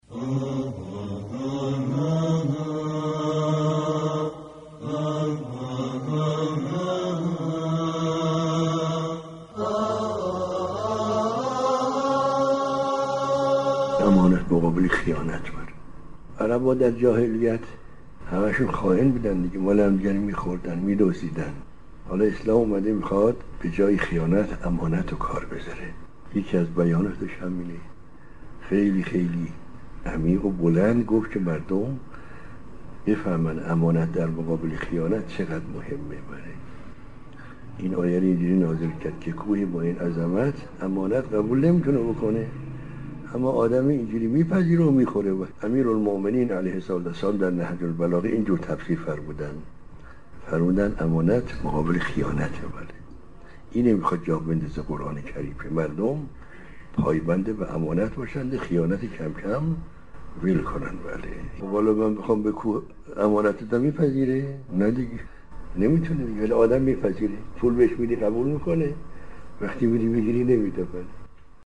به گزارش پایگاه اطلاع رسانی رادیو قرآن، در سخنرانی آیت‌الله خوشوقت كه، ایشان به شرح كلمه «امانت» در آیه 72 سوره احزاب پرداختند.